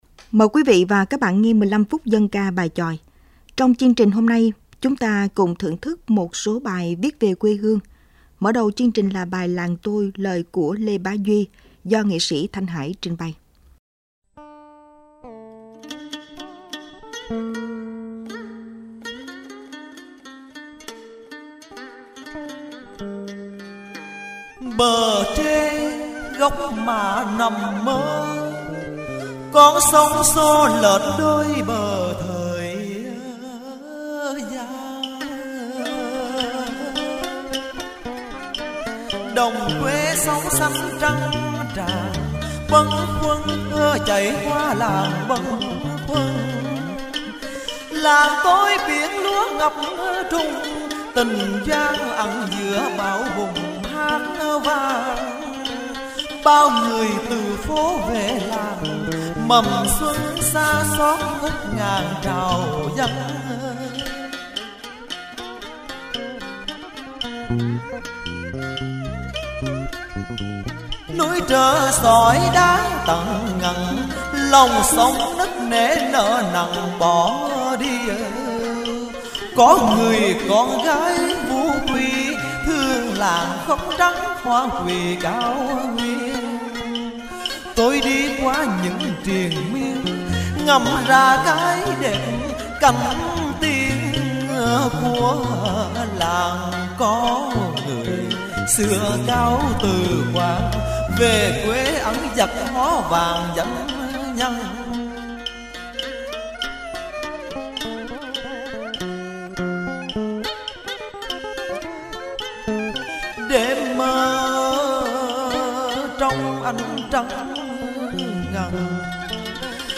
19-2-dan-ca.mp3